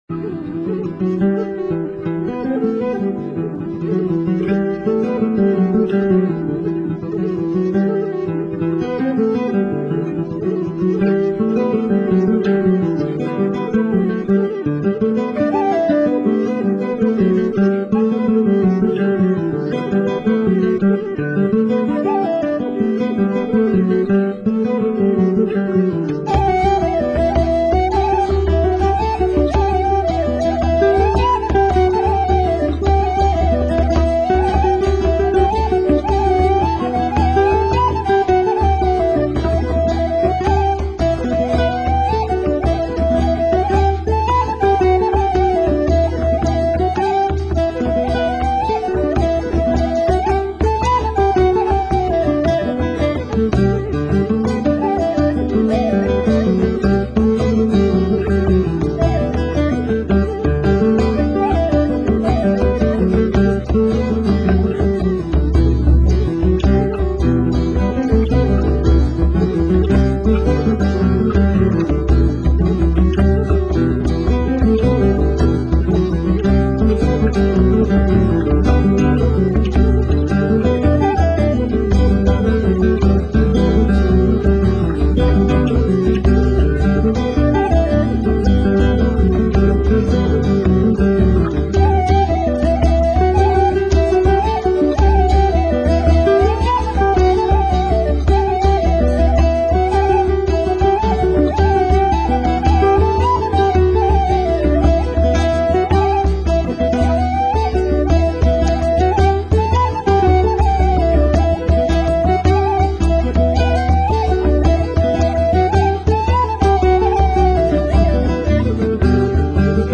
lungo le rotte della musica tradizionale celtica, tra Atlantico e Mediterraneo
The Kid on the Mountain (air/slip jig) 4.48